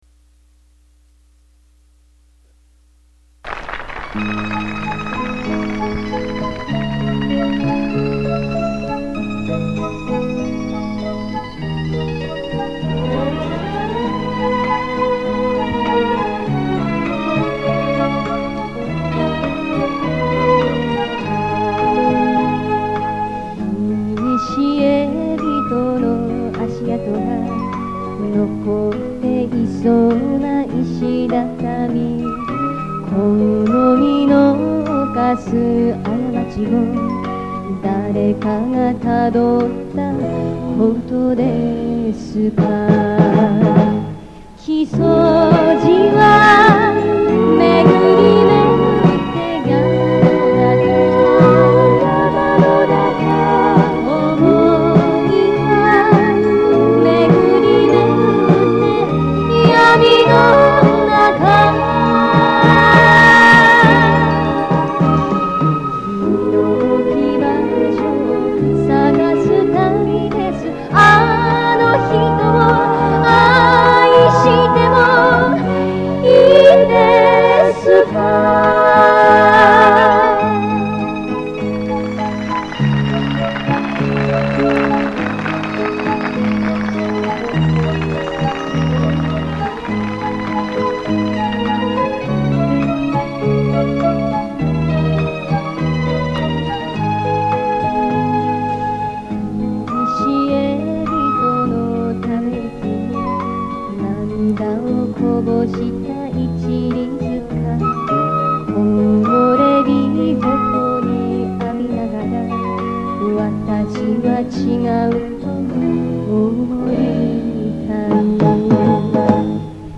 歌声は、1 ＆ 3番が収録されています。
（注）CassetteTapeからMP3に変換、
多少の雑音ご容赦ください。